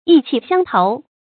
意氣相投 注音： ㄧˋ ㄑㄧˋ ㄒㄧㄤ ㄊㄡˊ 讀音讀法： 意思解釋： 意氣：志趣和性格。志趣和性格彼此十分投合。